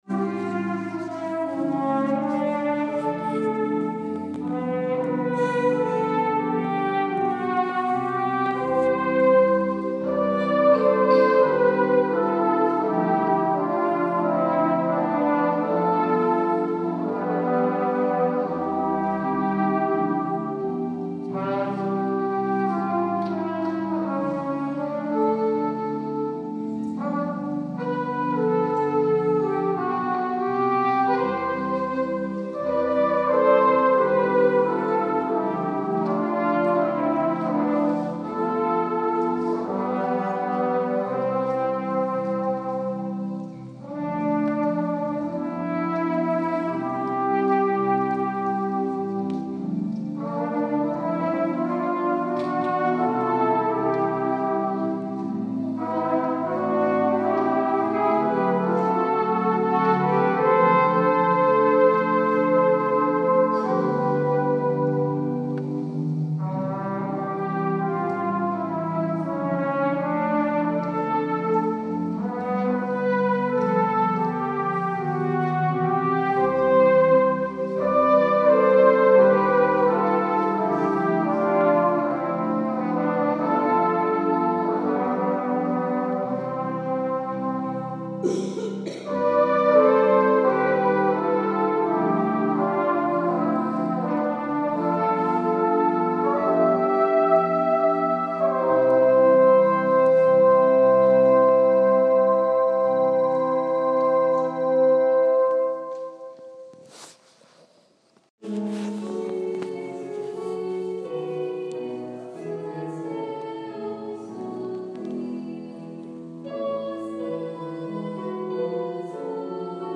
Musik og sang ved de ni læsninger.